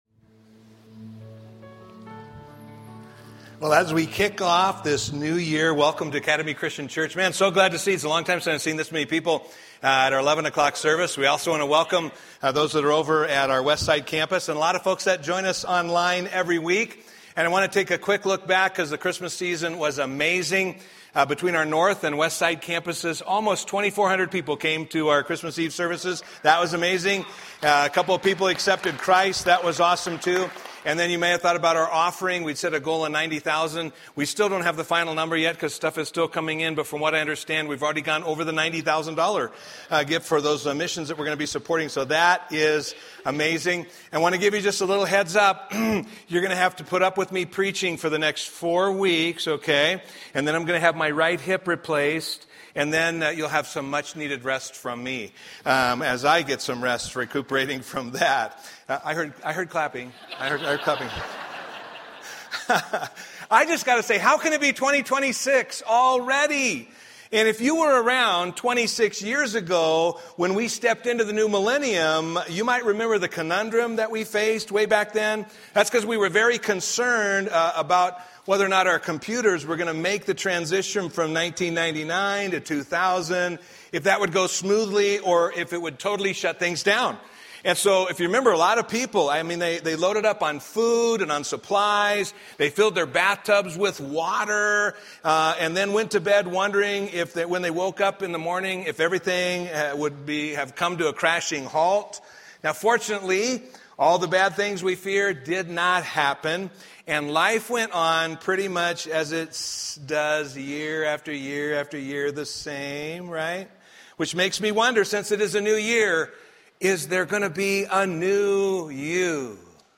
A collection of ACC Sunday Messages that are not a part of a Sermon Series